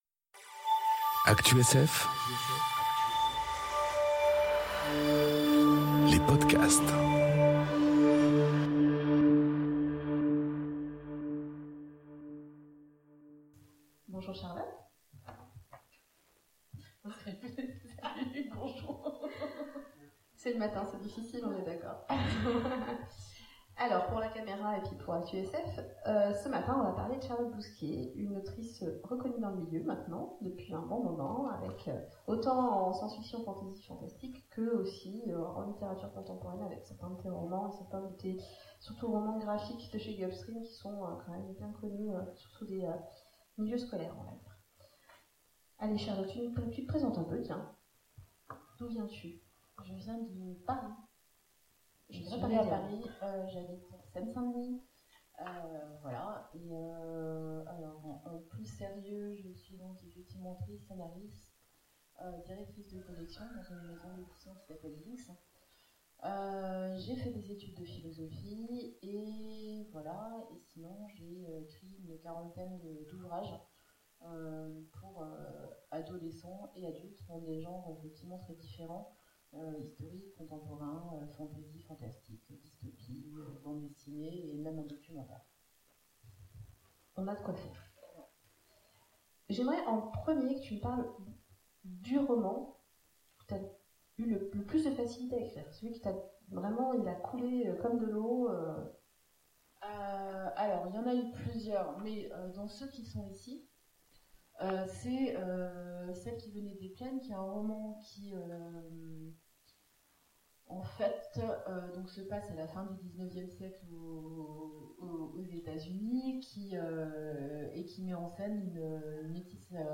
Aventuriales 2018
Rencontre avec un auteur Conference